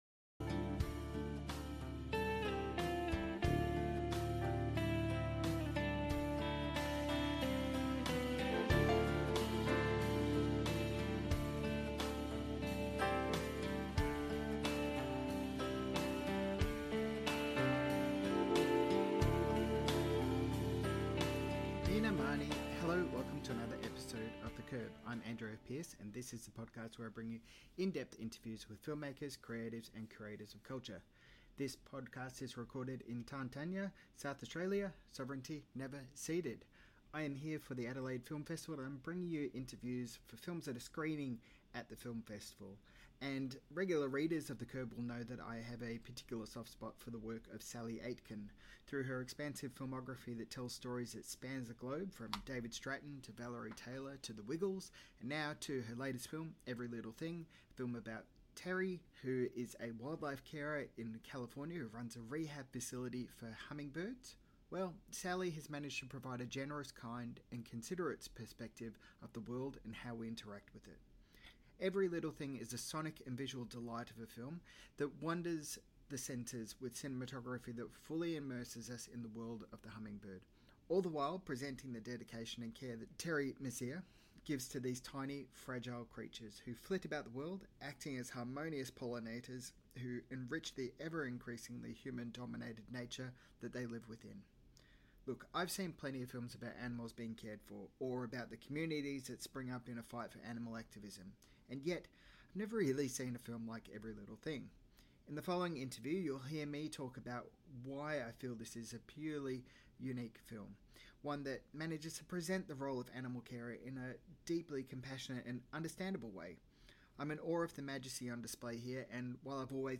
Adelaide Film Festival Interview